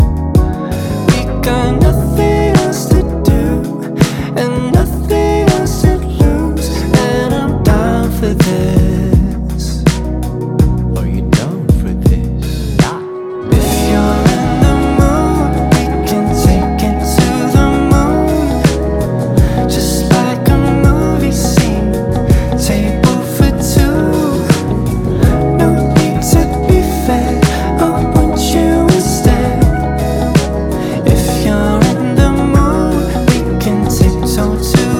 Жанр: Соул / R&b